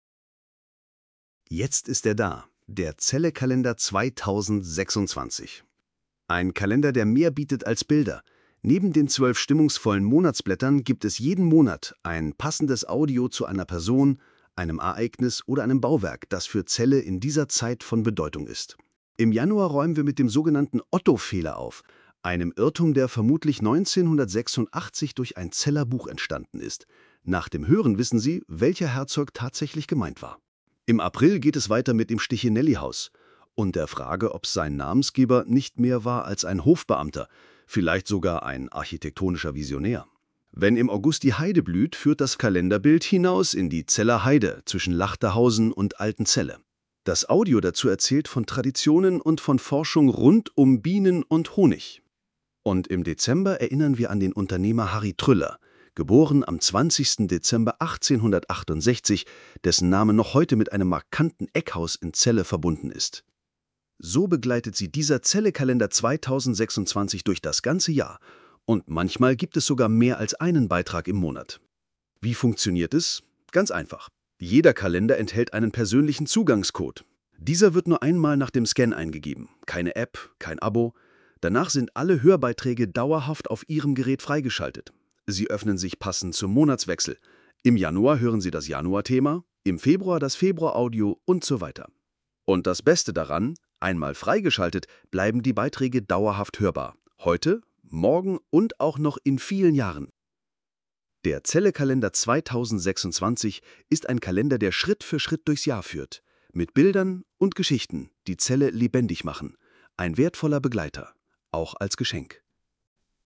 Celle Kalender 2026 – Der sprechende Kalender (Hör-Kalender, deutsch/englisch)